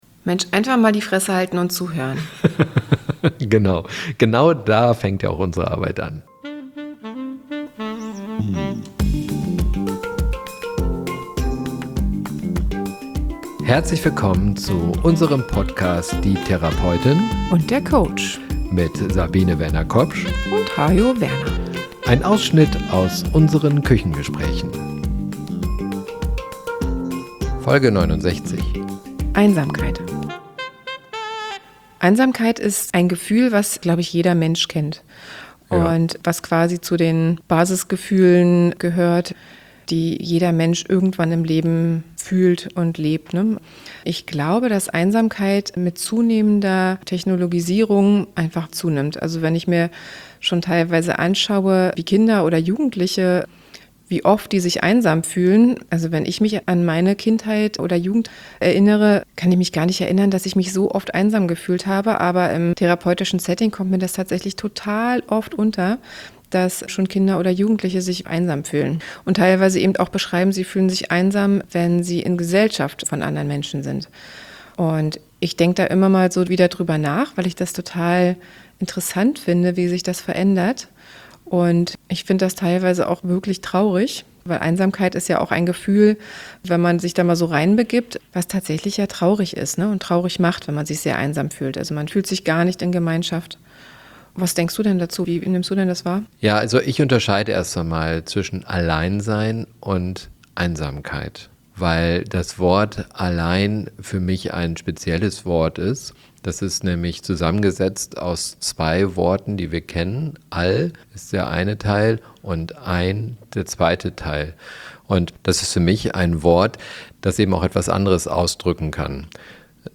Das offene Küchengespräch über die menschlichen Themen. Wir begleiten seit langer Zeit Menschen als Therapeutin und als Coach und tauschen uns oft über menschliche und zwischenmenschliche Themen aus.